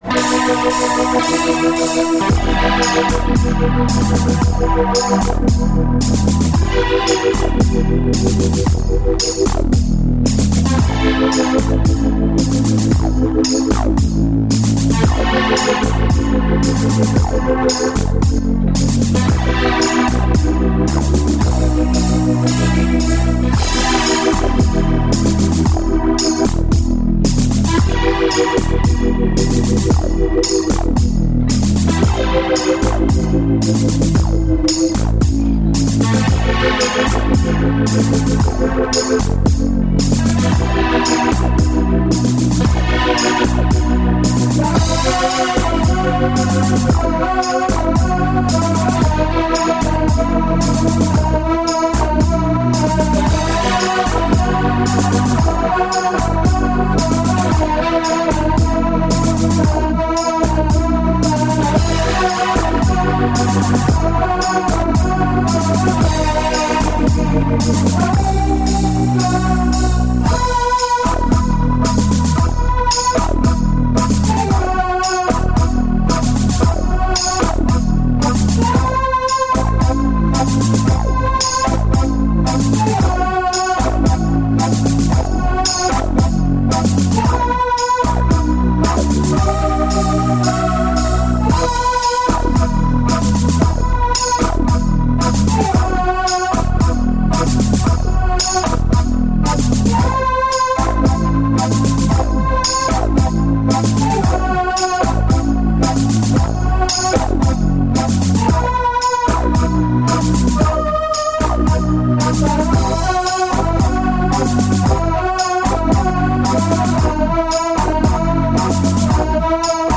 демо версией песни